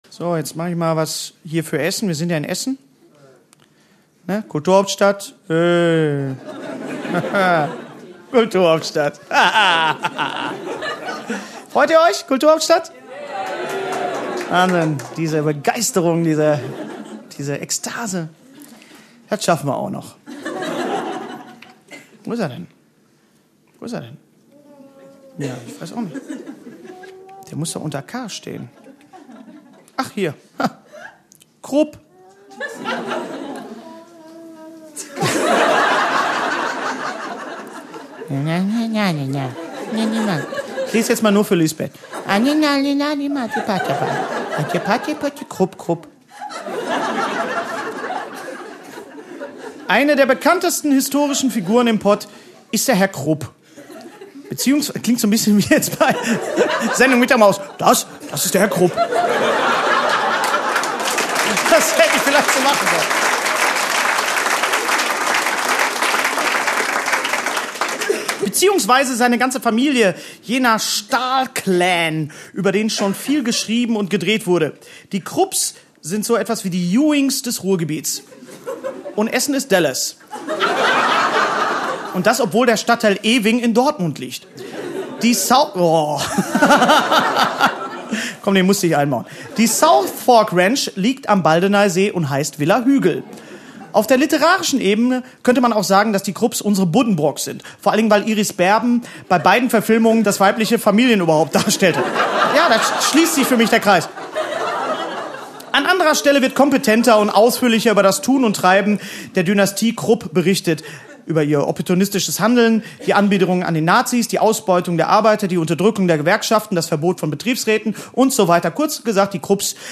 Komma lecker bei mich bei Kleines Ruhrpott-Lexikon: 1 CD Hennes Bender (Autor) Hennes Bender (Sprecher) Audio-CD 2010 | 3.